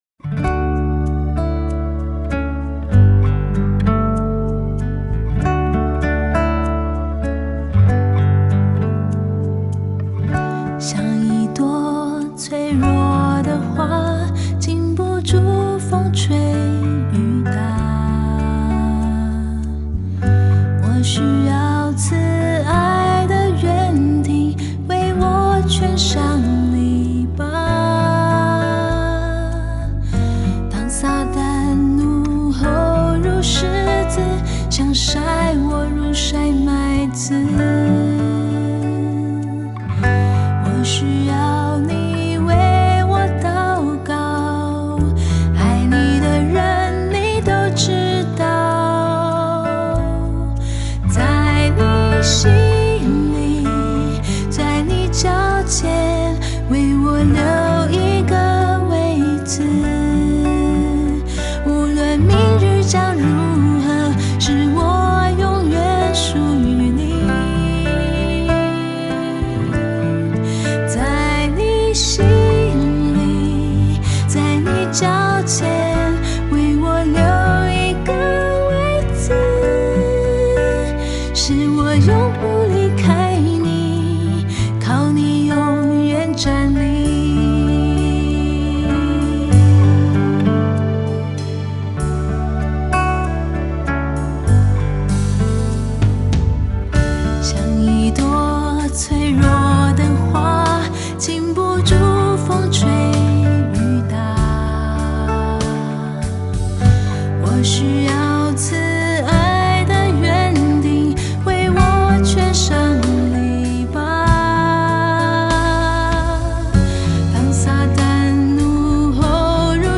mp3 原唱音樂
前奏 → 主歌 → 副歌 1 → 間奏 → 主歌 → 副歌 1 → 副歌 2 → 副歌 3 (放慢)